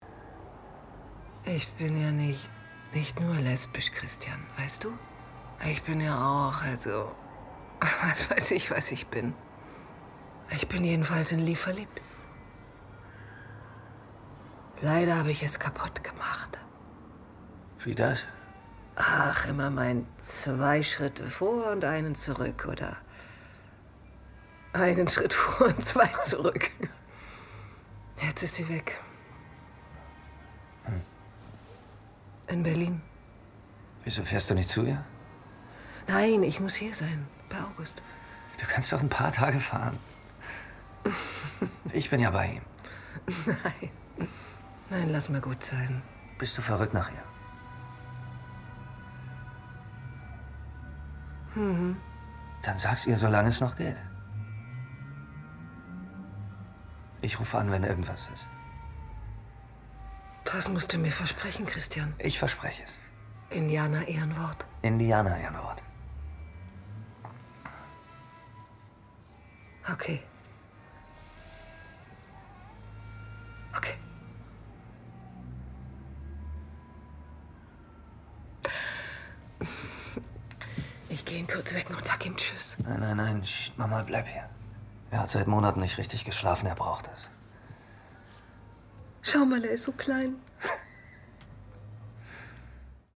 Synchron / Serienhauptrolle